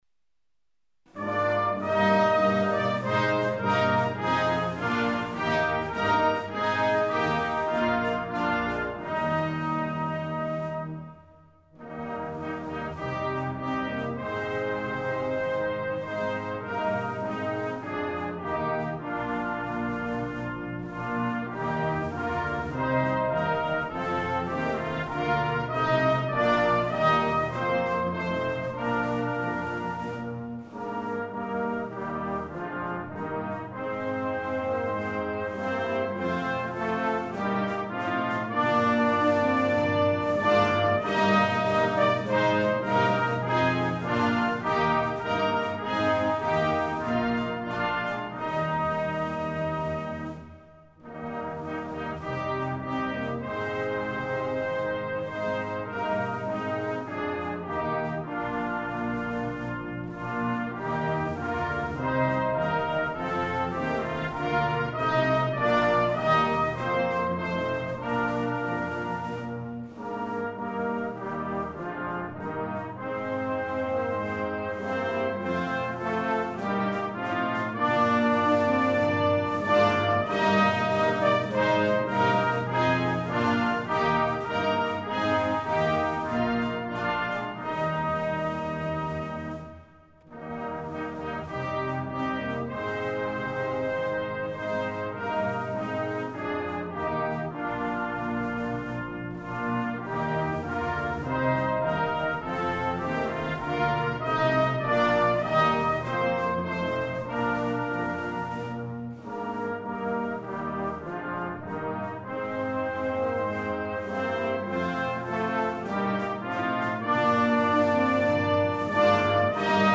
Snare Drum
Band Accomp